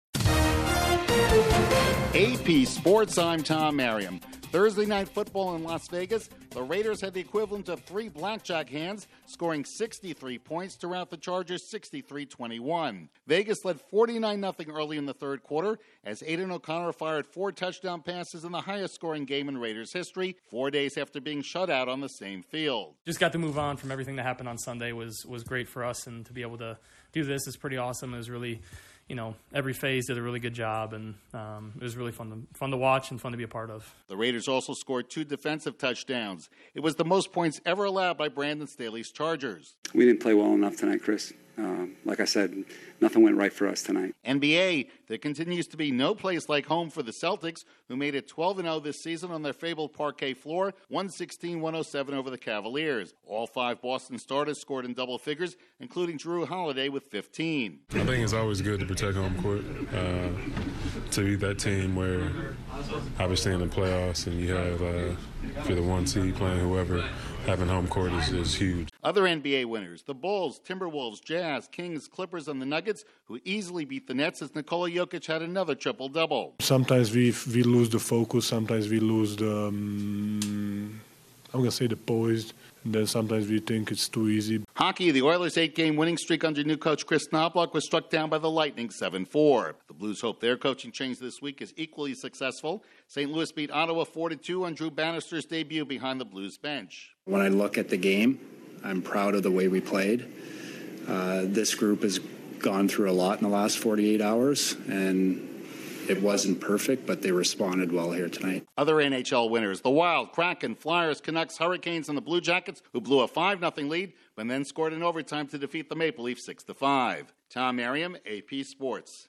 The Raiders stick it to the Chargers, the Celtics remain perfect at home, another triple double for Nicola Jokic, and the Blues coaching change works as they drew it up. Correspondent